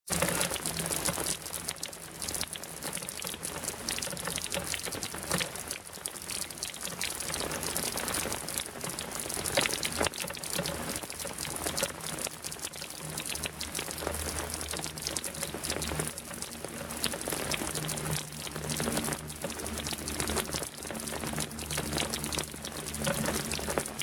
Clac, clac, clac.
pluie2.mp3